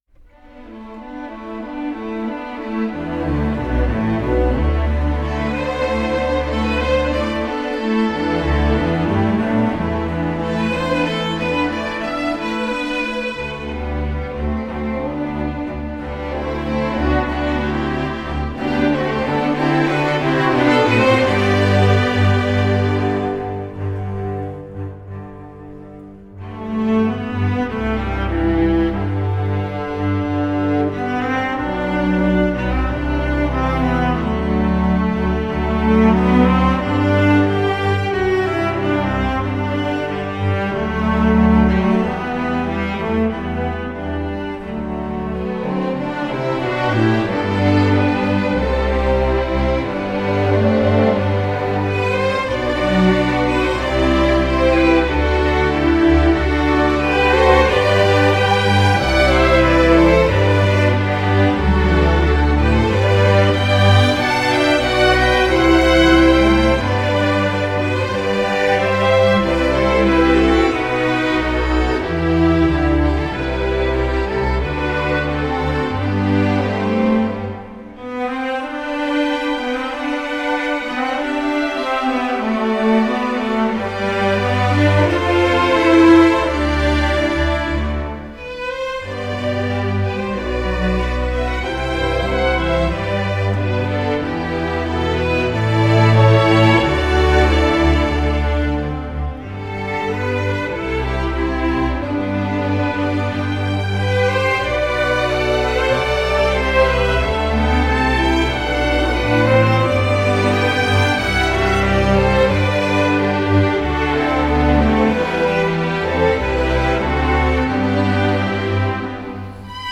Category: String Orchestra